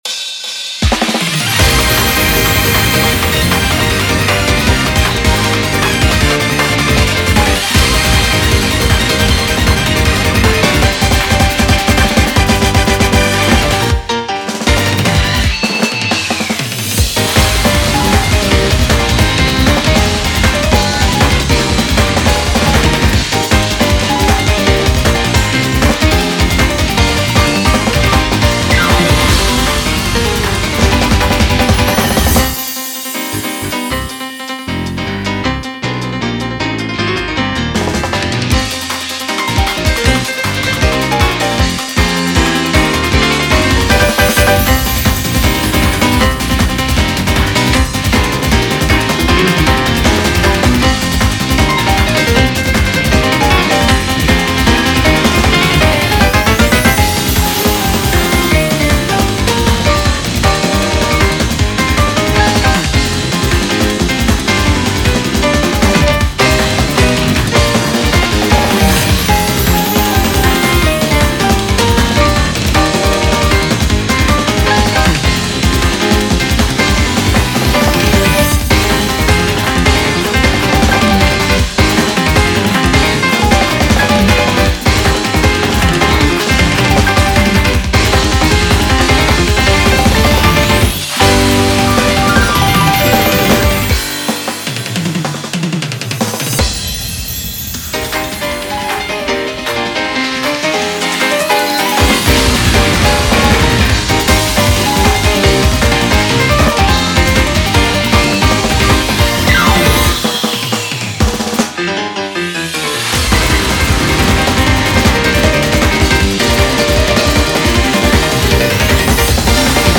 BPM156
Audio QualityMusic Cut